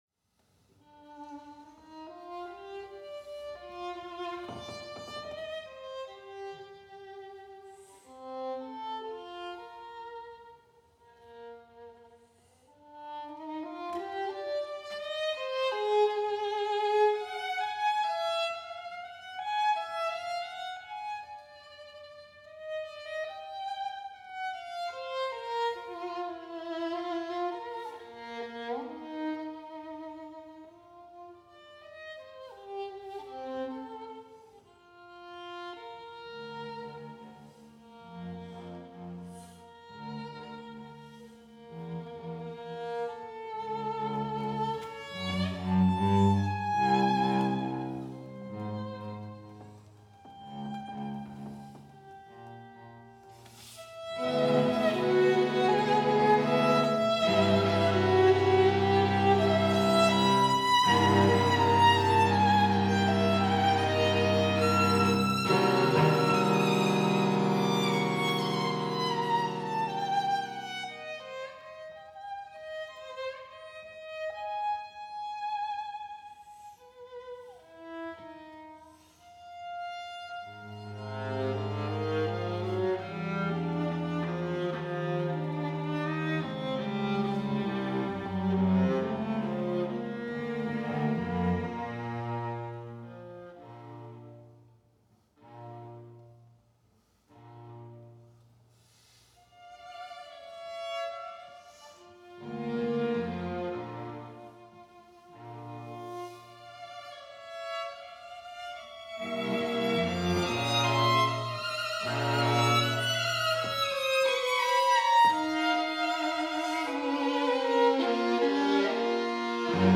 Instrumentation Category:String Quartet
Venue: St. Brendan’s Church
Instrumentation: 2vn, va, vc